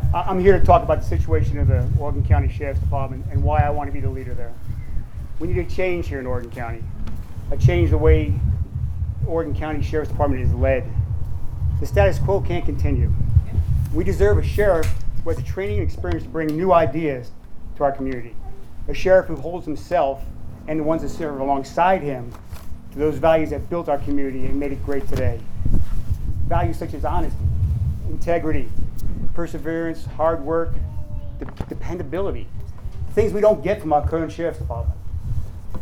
A Republican Meet And Greet was held Saturday in the Thayer City park for candidates running for office in the August Election.
The Meet and Greet began with each candidate making a brief statement.